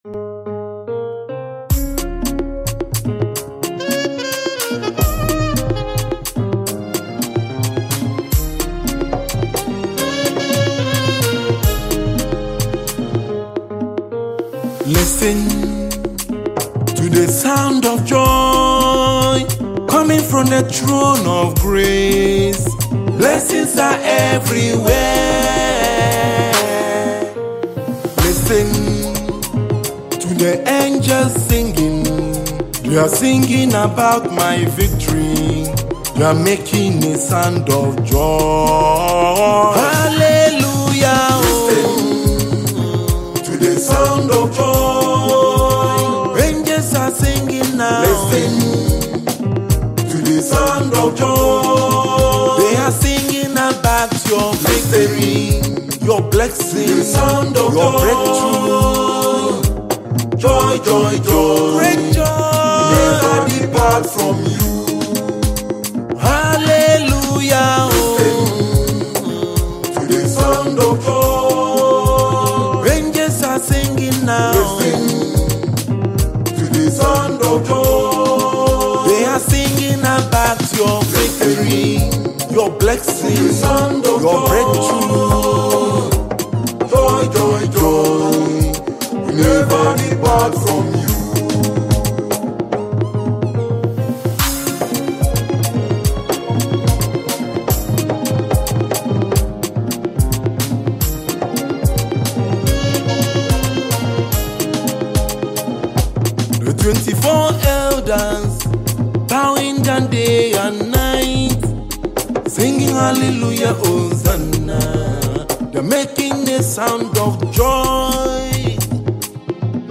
Gospel
songwriter and saxophonist.
music comes heavily blended in traditional African rhythms